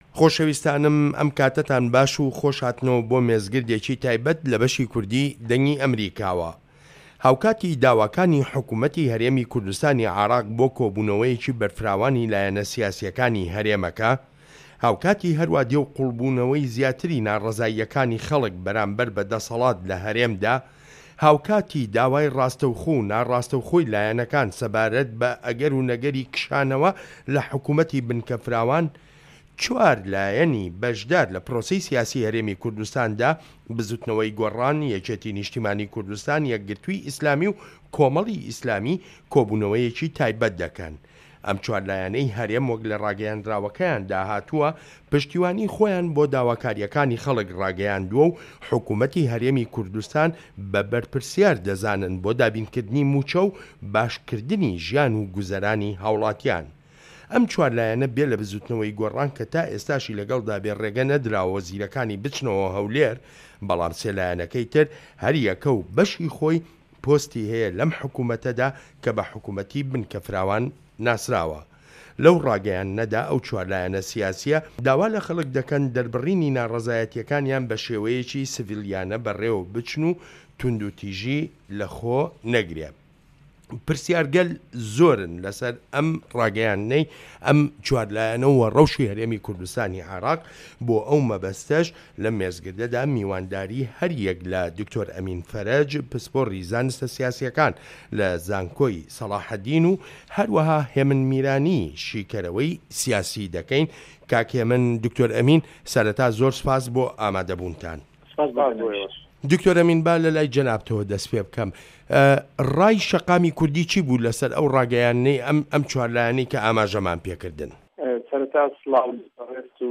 مێزگرد: لایەنەکانی هەرێم و ناڕەزایی خەڵک